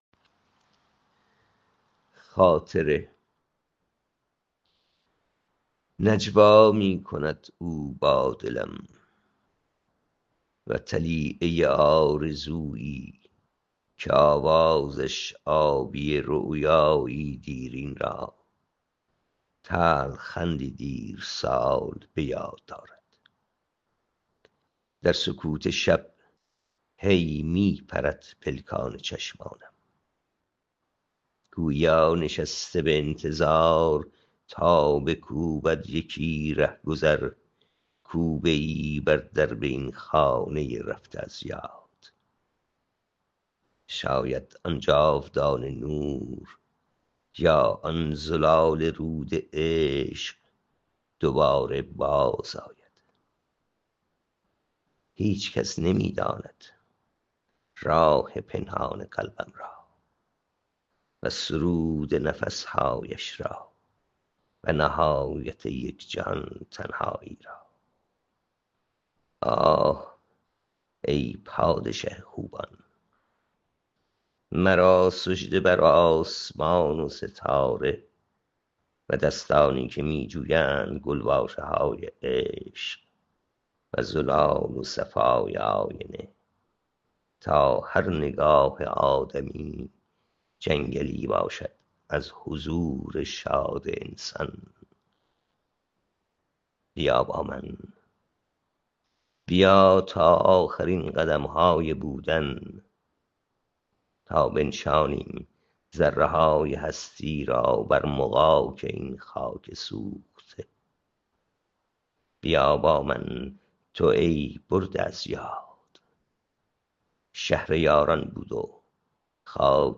این شعر را با صدای شاعر گوش کنید